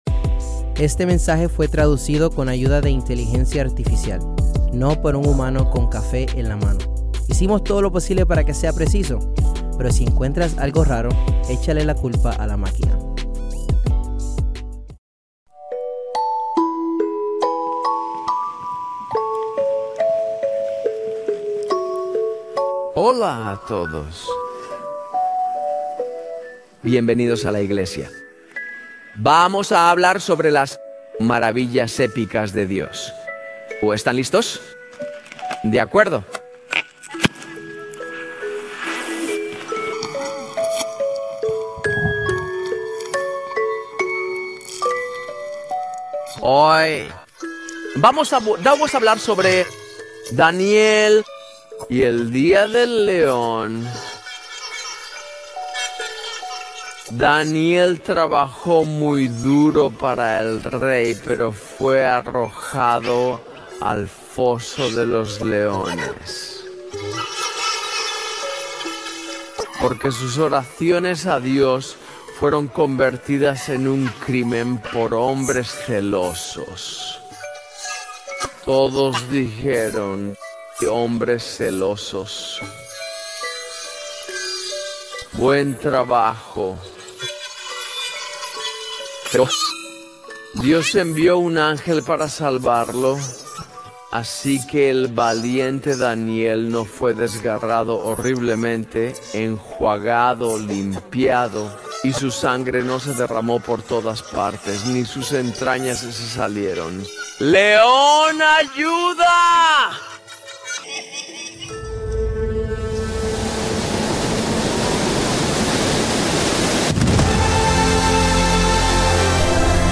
Grabado en vivo en Crossroads Church en Cincinnati, Ohio.